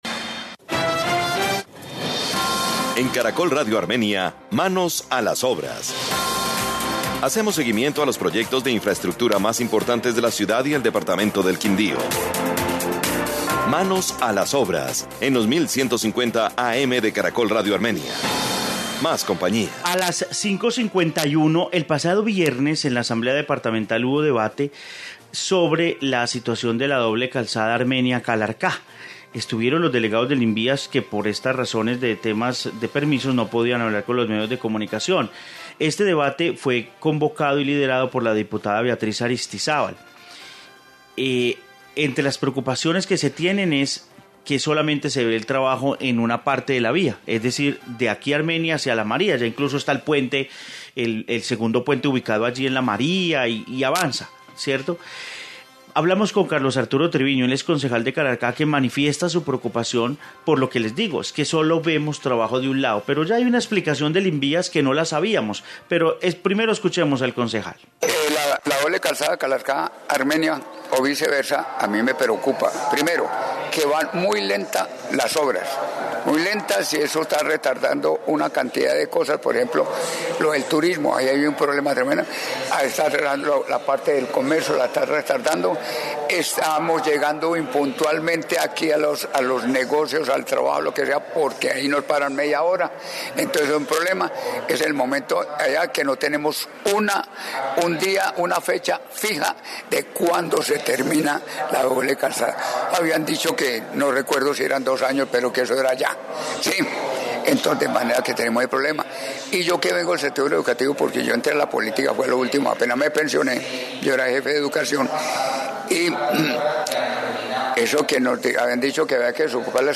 Informe doble calzada Armenia - Calarcá, Quindío